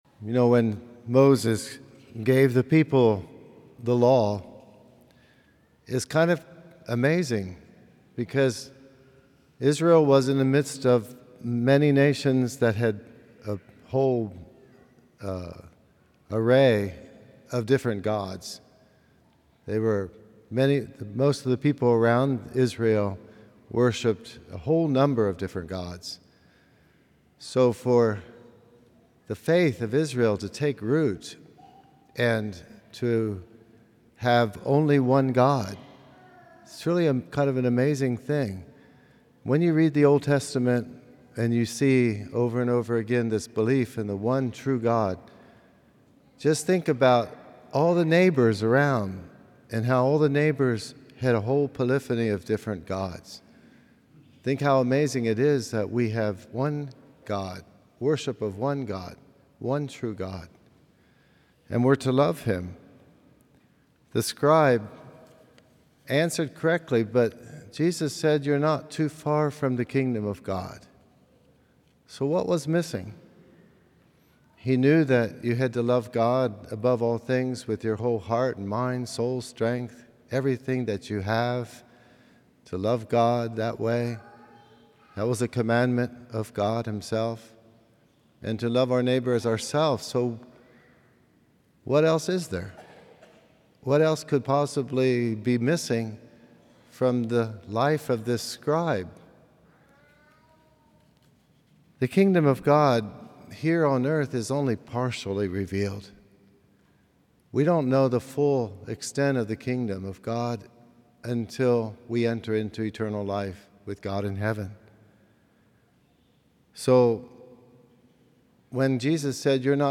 Homilies - Prince of Peace Catholic Church & School